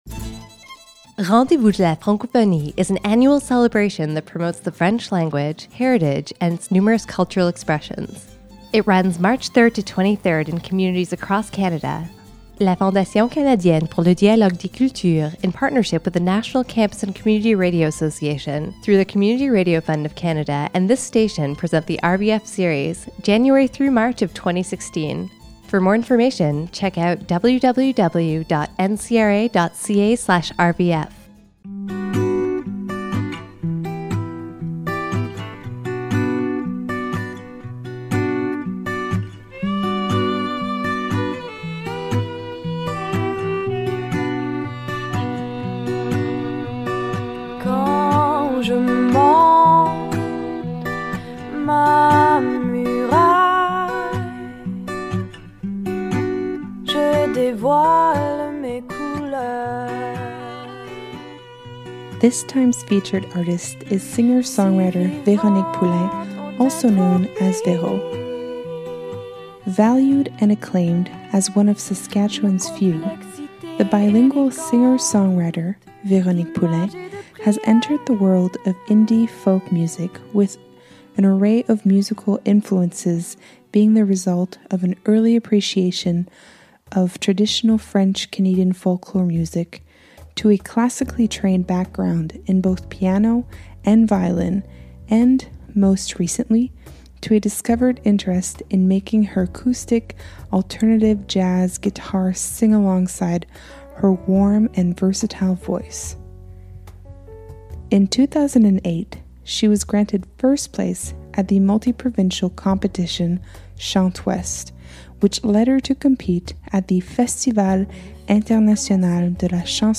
Capsules containing information, interviews, and music from different Western Canadian Francophone artists.